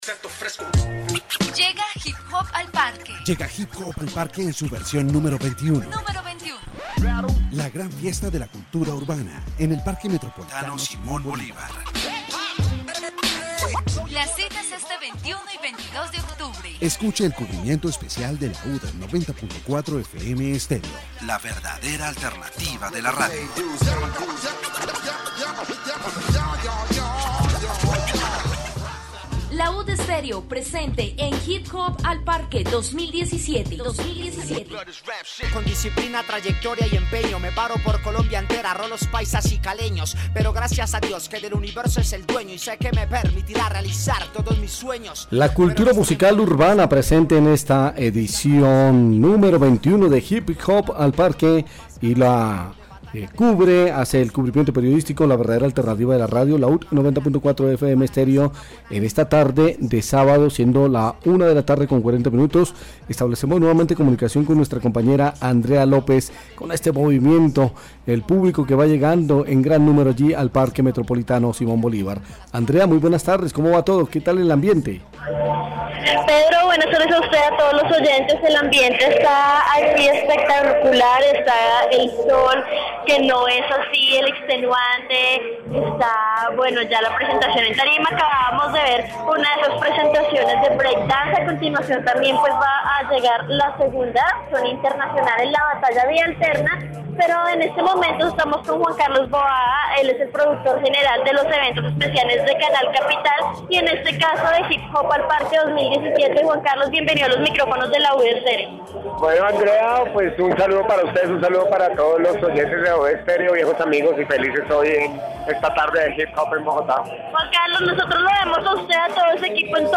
La transmisión, realizada por LAUD 90.4 FM Estéreo, resalta la magnitud y vitalidad del evento, incluyendo presentaciones en vivo de break dance, batallas de rap y otras expresiones artísticas.